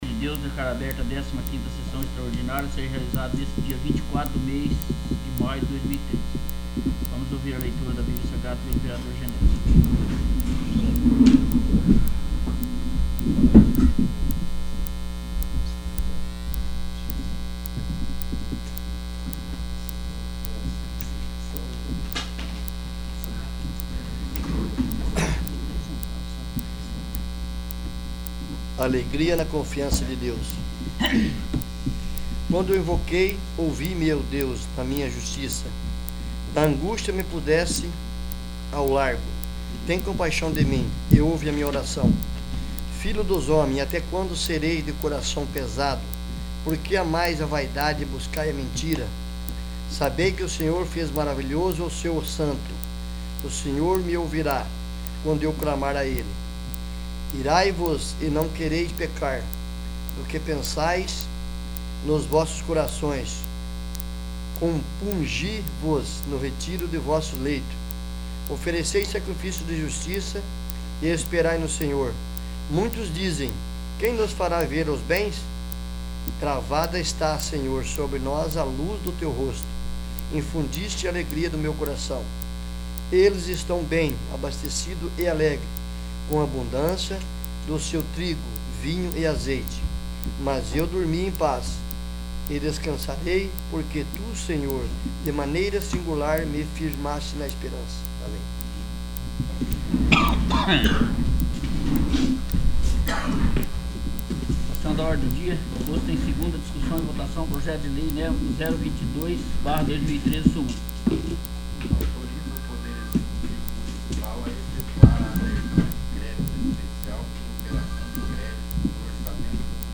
15º. Sessão Extraordinária